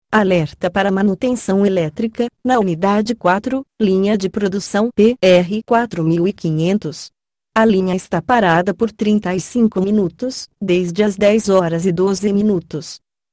-Sistema de alerta via voz (pabx),  os alertas são anunciados com voz feminina (Realspeak, o mesmo sistema que a Embratel utiliza), dando informações claras do local de alerta e suas necessidades.